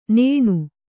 ♪ nēṇu